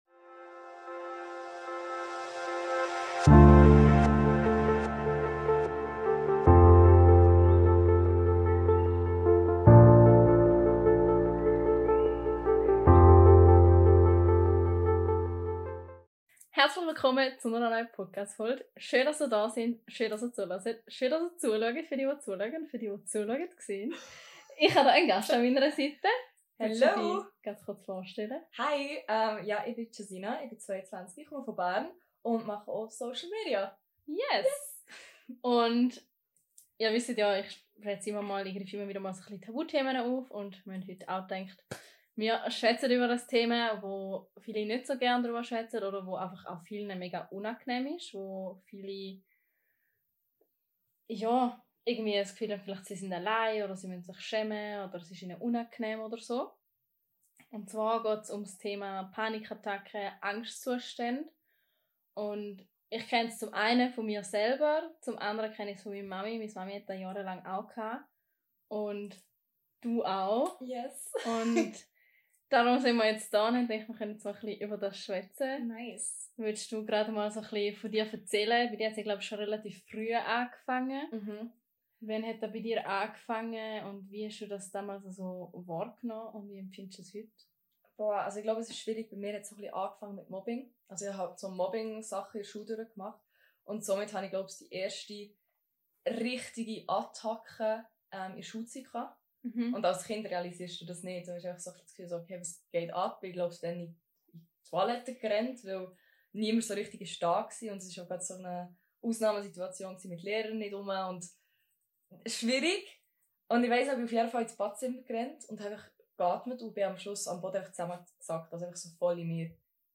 Dieses Gespräch ist eine wertvolle Ressource für alle, die ähnliche Herausforderungen erleben oder einfach mehr über das Thema erfahren möchten. Diese Episode ist eine Einladung, Mut zu zeigen, die eigenen Ängste zu erkennen und Wege zu ihrer Überwindung zu finden.